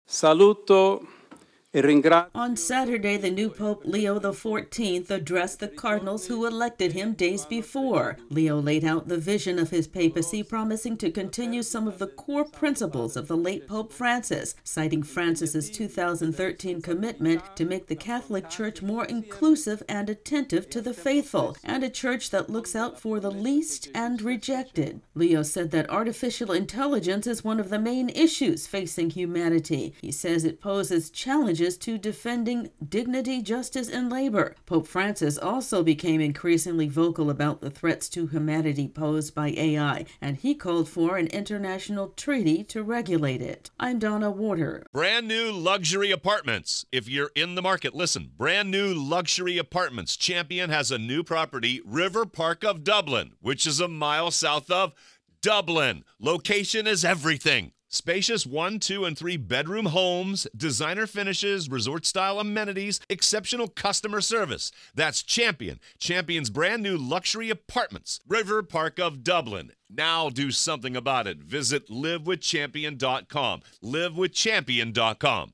Pope Leo XIV lays out the vision of his papacy. AP correspondent